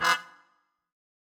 GS_MuteHorn-Bdim.wav